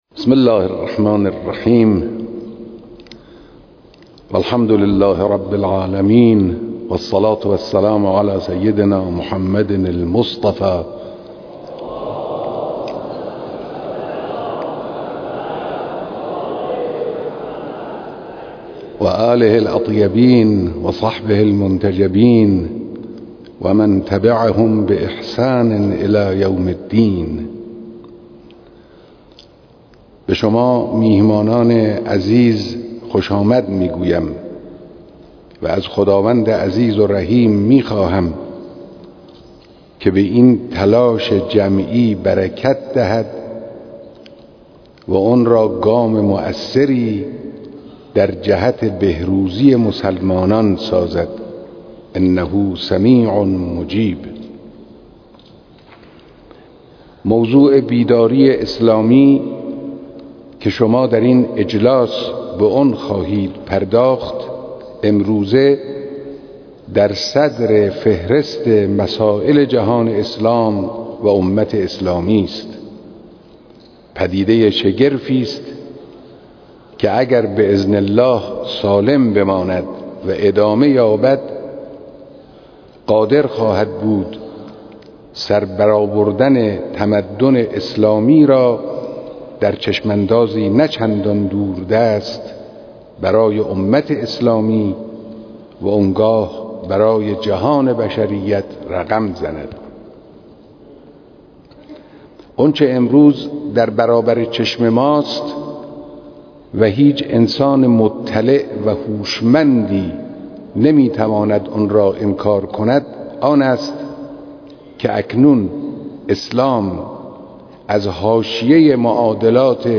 بیانات در اجلاس جهانی علمای امت و بیداری اسلامی